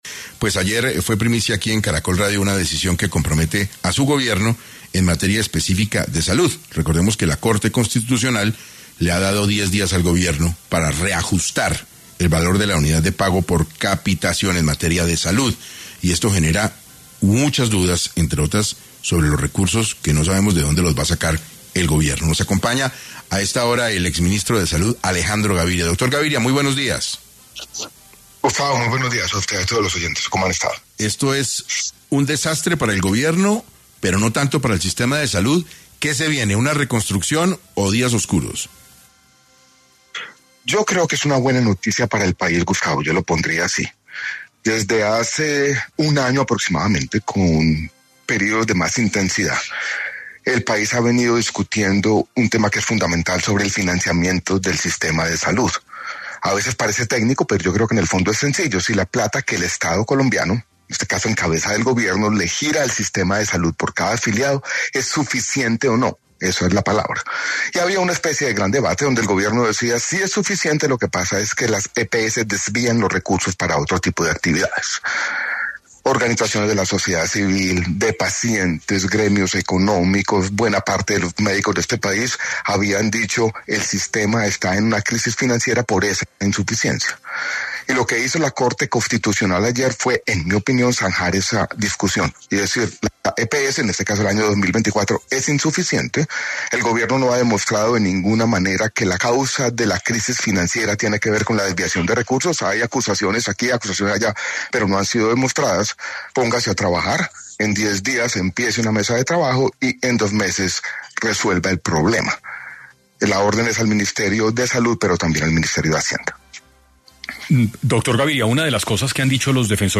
En 6AM de Caracol Radio estuvo Alejandro Gaviria, exministro de salud y se refirió a la declaración de la Corte Constitucional sobre la UPC.